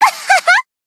BA_V_Mutsuki_Battle_Shout_2.ogg